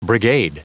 Prononciation du mot brigade en anglais (fichier audio)